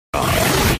menu-direct-click.mp3